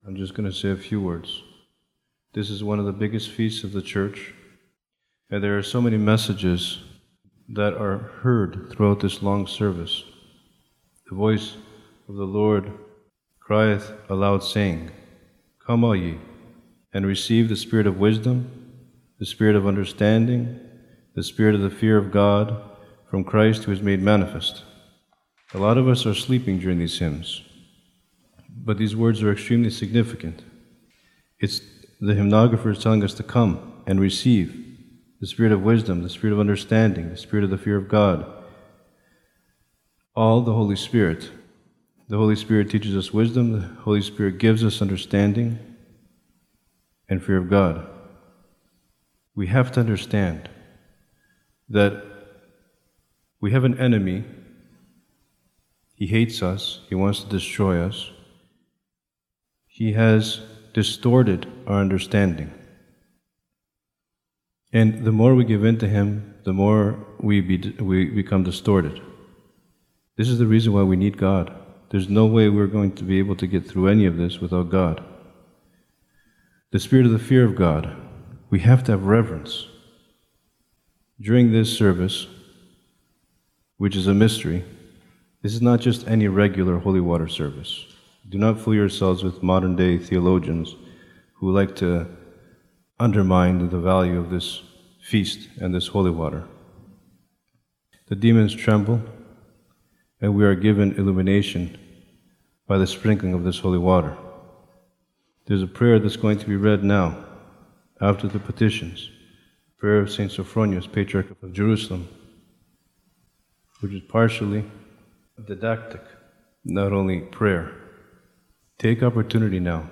Sermon delivered on the first blessing of the waters by Metropolitan Demetrius, Theophany 2020
sermon-we-must-pay-atention-an-awesome-mystery-is-before-us.mp3